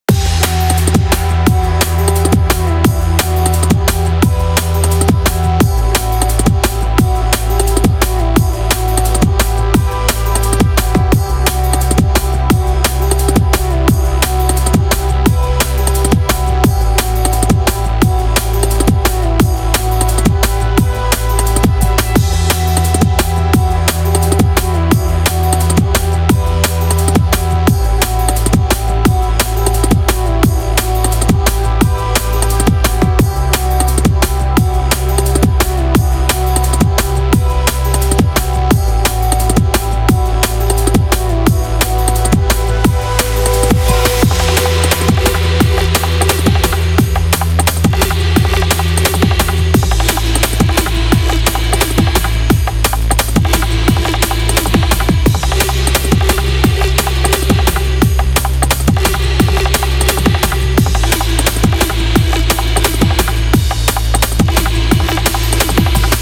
(Pop, Trap, DnB, reklamní spot,...)